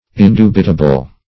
Indubitable \In*du"bi*ta*ble\, a. [L. indubitabilis: cf. F.
indubitable.mp3